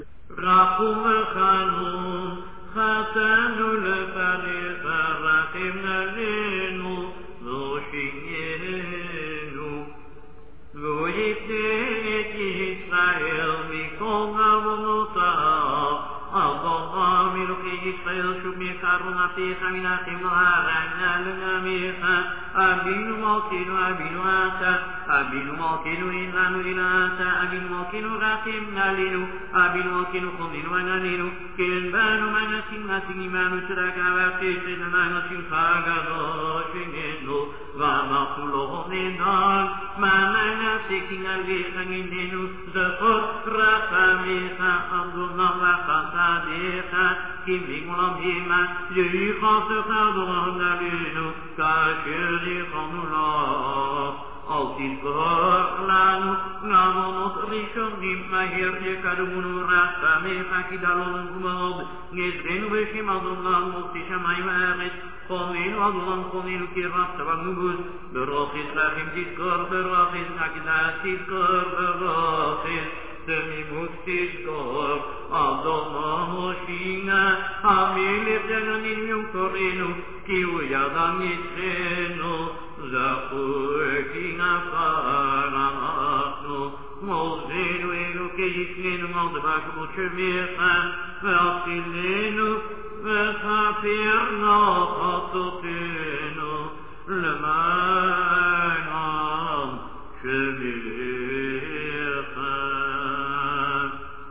Chazzan
[5] This line is said silently.